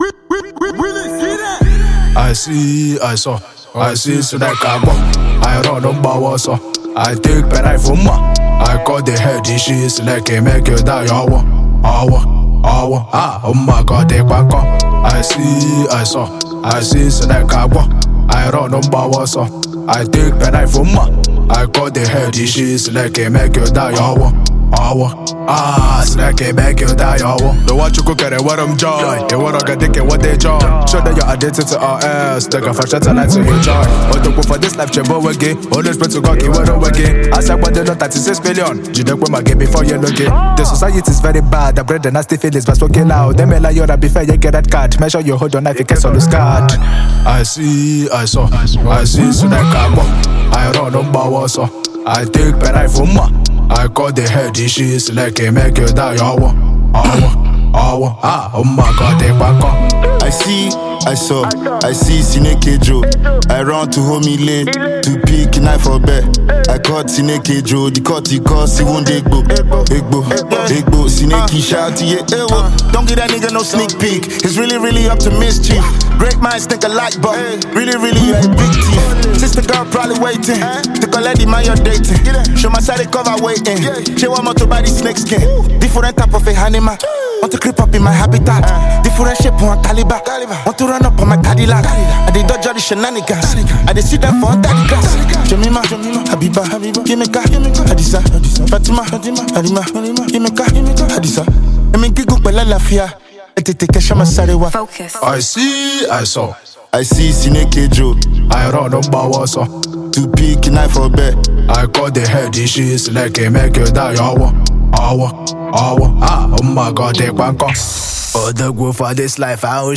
Nigerian Rapper